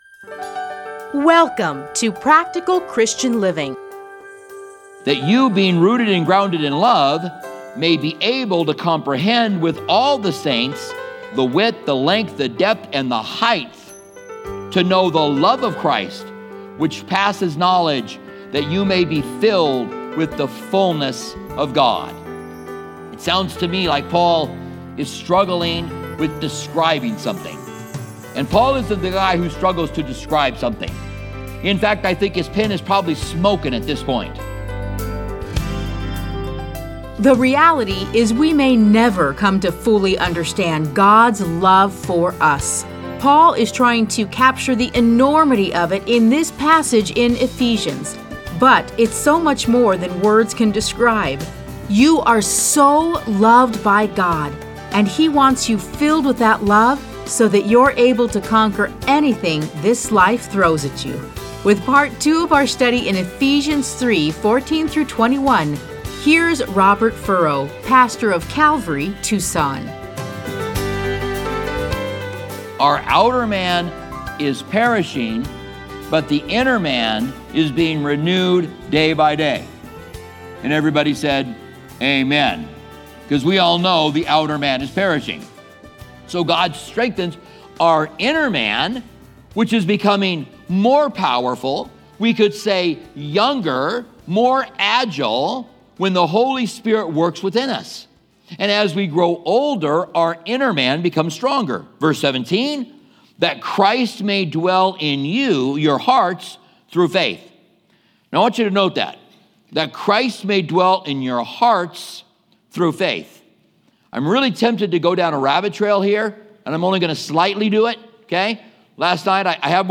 Listen to a teaching from Ephesians 3:14-21.